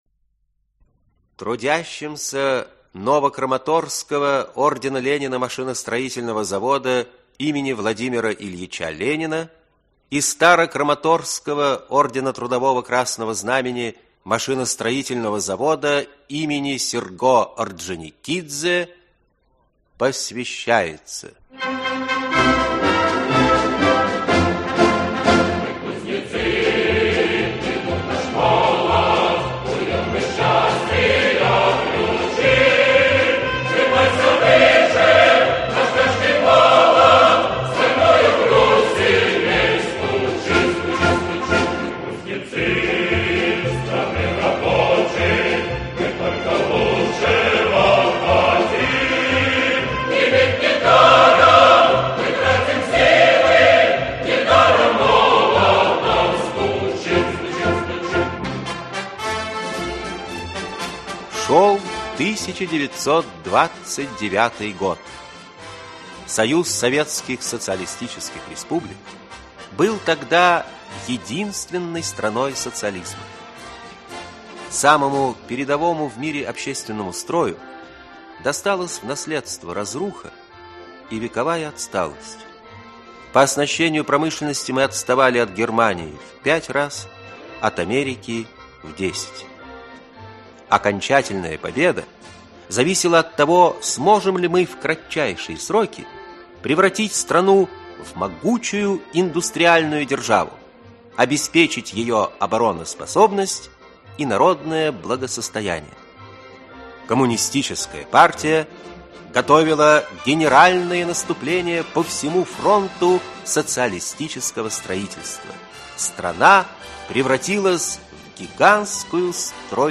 Аудиокнига Подарок наркома. Часть 1. «Заводу быть» | Библиотека аудиокниг
«Заводу быть» Автор Лев Иванович Митрофанов Читает аудиокнигу Актерский коллектив.